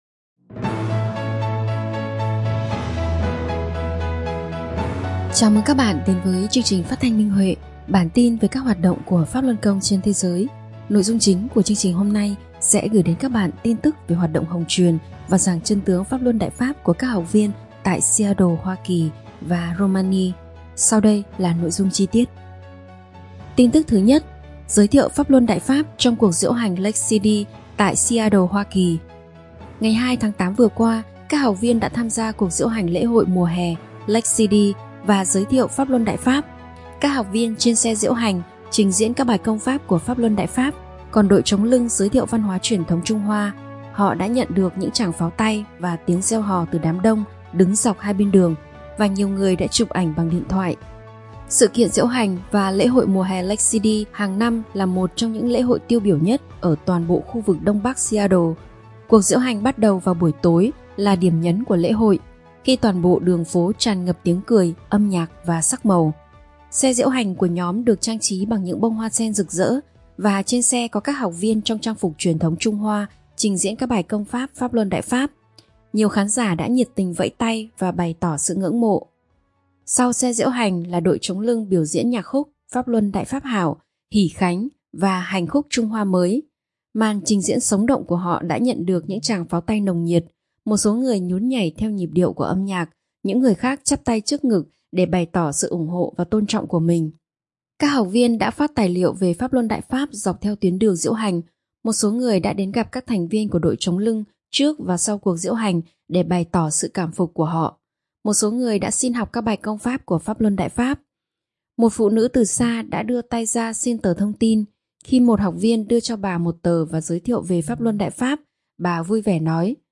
Chào mừng các bạn đến với chương trình phát thanh Minh Huệ. Chúng tôi muốn gửi đến các bạn tin tức về hoạt động giảng chân tướng và hồng truyền Đại Pháp của Pháp Luân Đại Pháp trên thế giới.